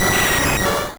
Cri de Nosferalto dans Pokémon Rouge et Bleu.